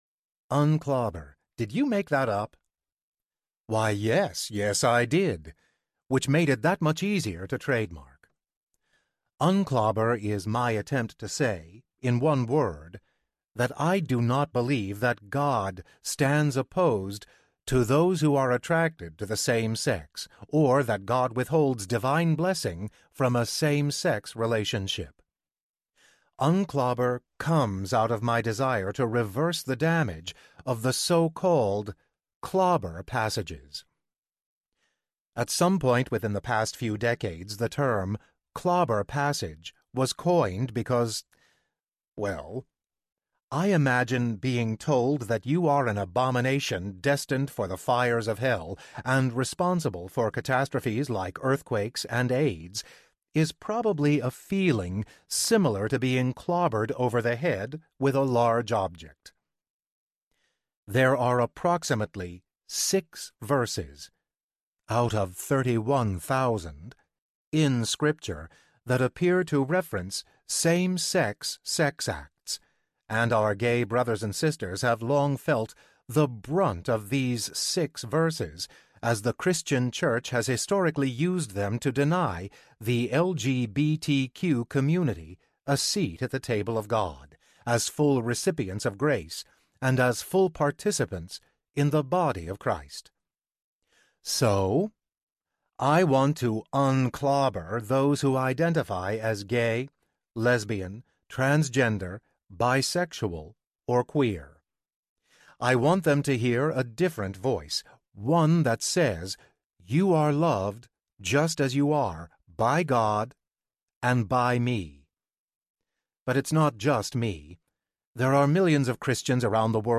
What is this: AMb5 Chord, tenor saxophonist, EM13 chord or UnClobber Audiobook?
UnClobber Audiobook